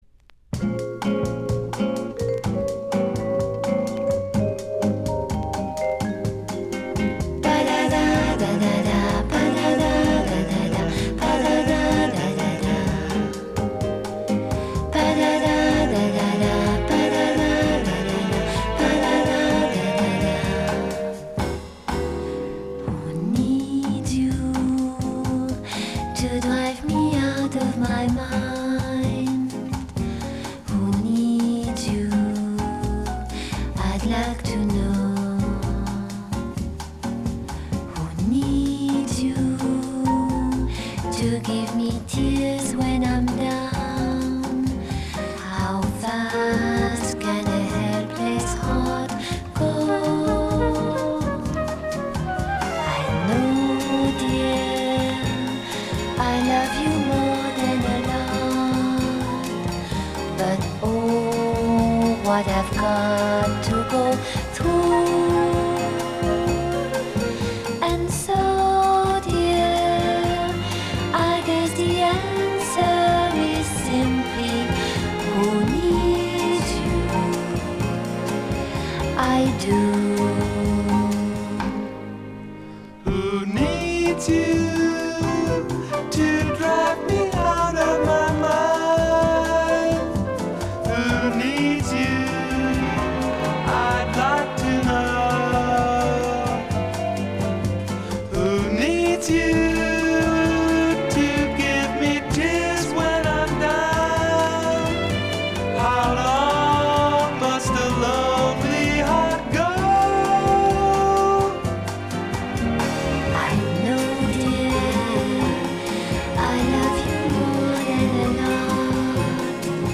Genre: Pop, Vocal, Easy Listening